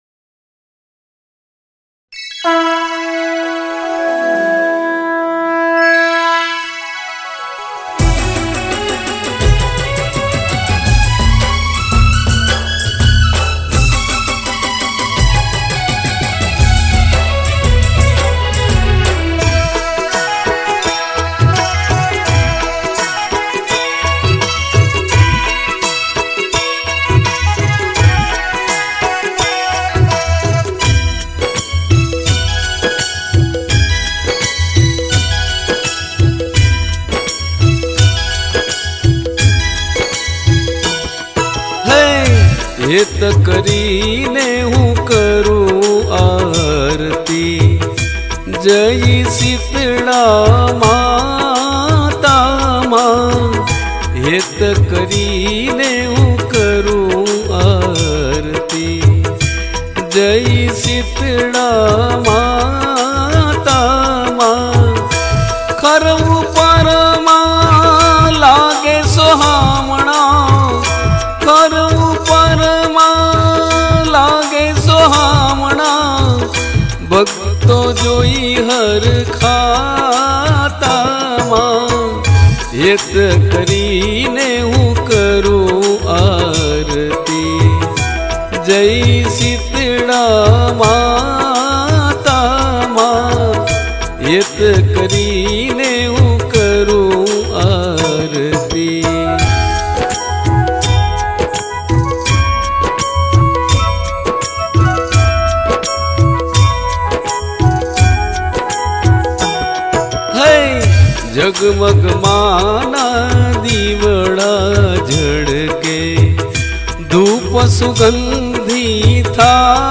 Gujarati Aarti